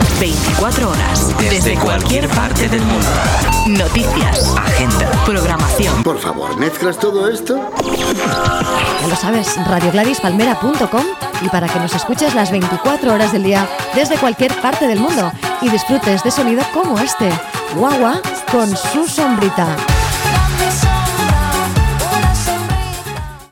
Llatina
Indicatiu amb adreça a Internet i tema musical